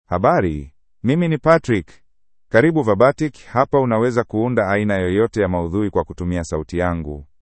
MaleSwahili (Kenya)
Patrick — Male Swahili AI voice
Patrick is a male AI voice for Swahili (Kenya).
Voice sample
Male
Patrick delivers clear pronunciation with authentic Kenya Swahili intonation, making your content sound professionally produced.